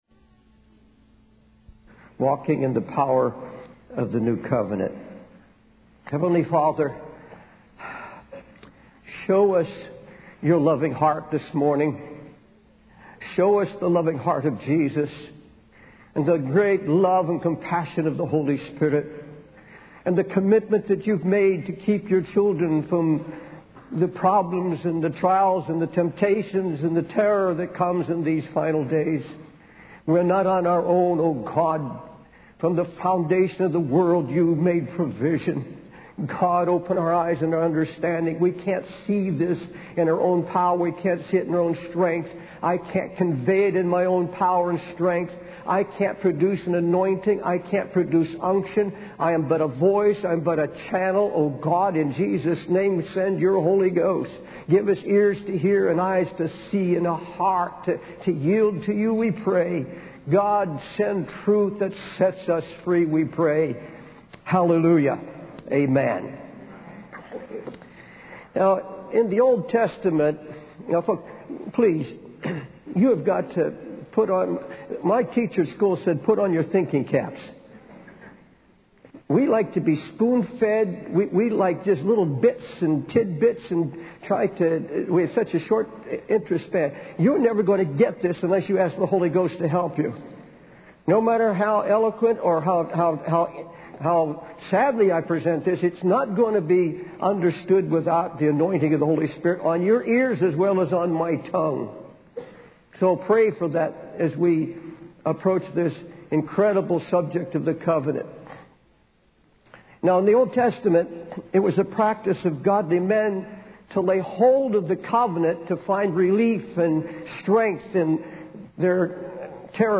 In this sermon, the preacher explores the story of Isaac sending his son Jacob on a journey with nothing, despite being a wealthy man.